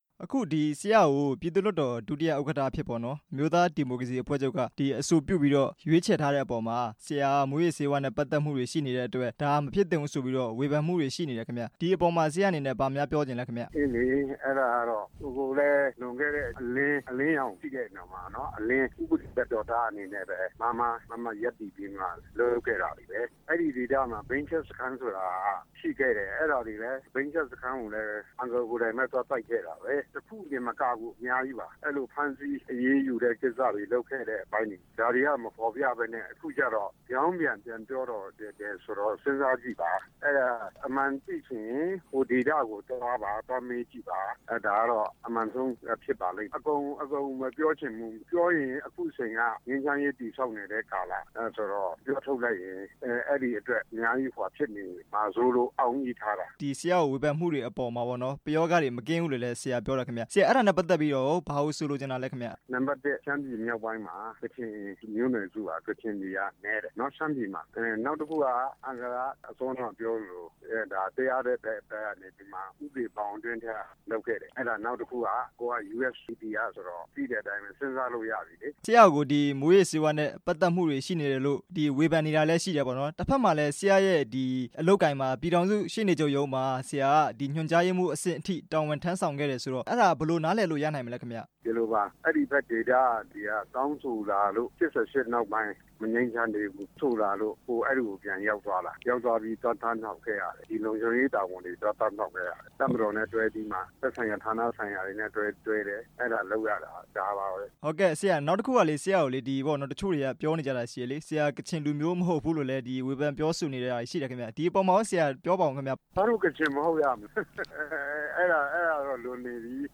ဦးတီခွန်မြတ် ကို မေးမြန်းချက်